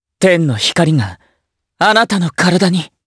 Lucias-Vox_Skill4_jp.wav